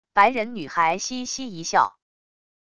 白人女孩嘻嘻一笑wav音频